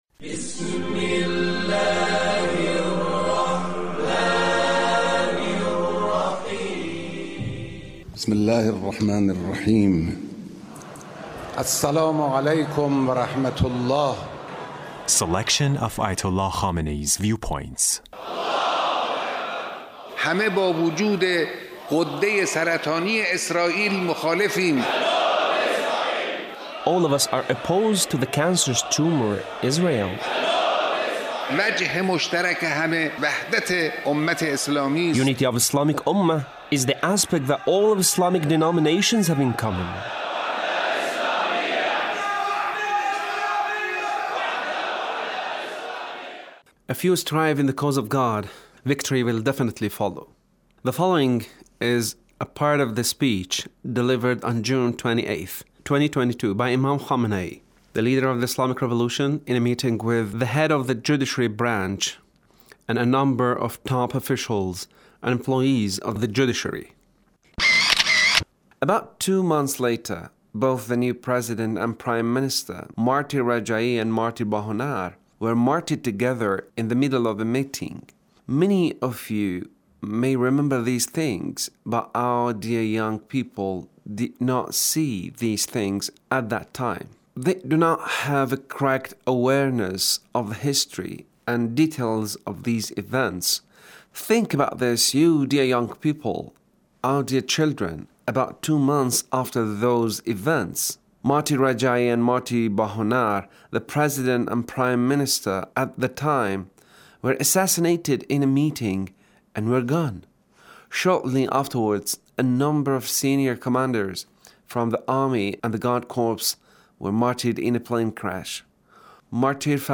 Leader's Speech with Judiciary Officials